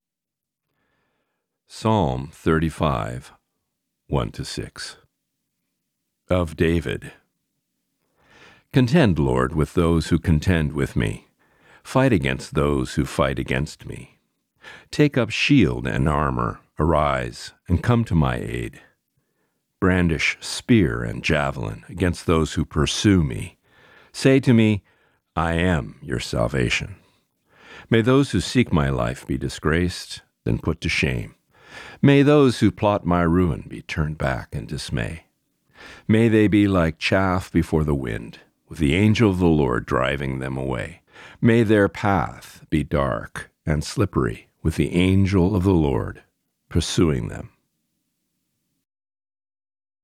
Today’s Reading: Psalm 35:1-6